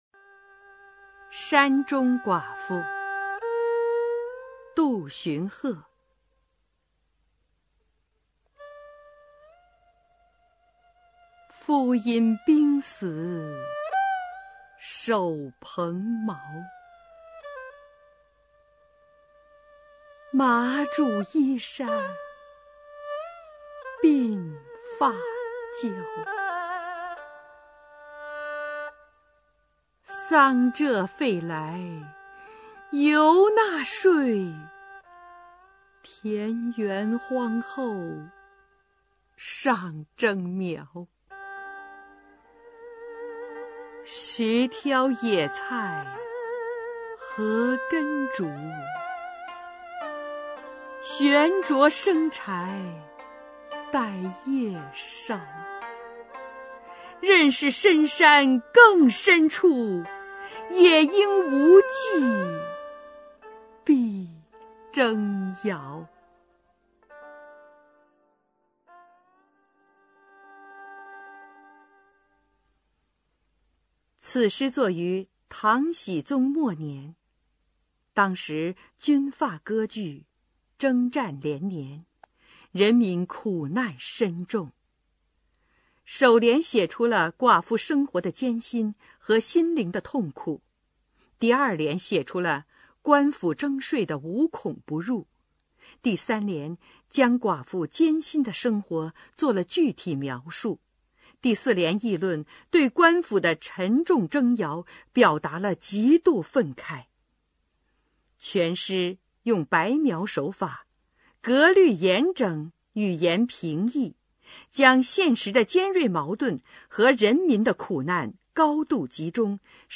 [隋唐诗词诵读]杜荀鹤-山中寡妇 古诗文诵读